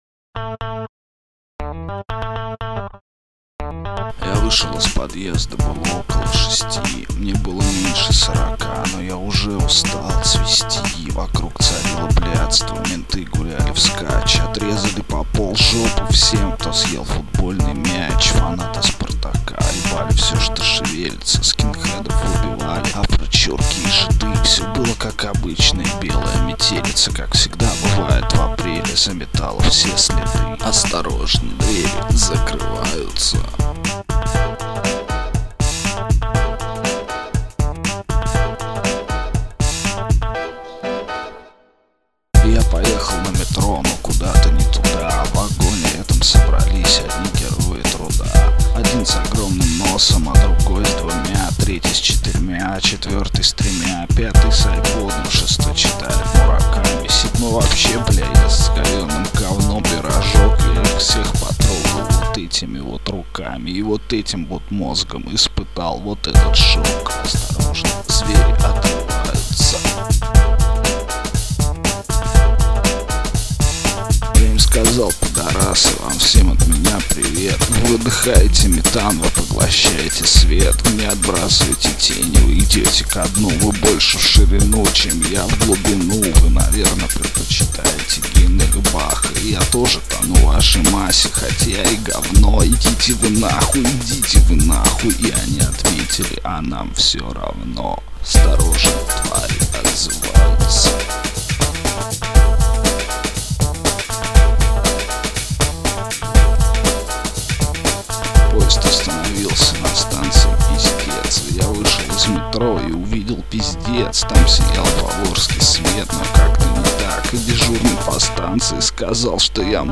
попробовал записать, хотя до этого рэп не читал
музыка - сэмплы к Reason 3:
podzemny_rap.mp3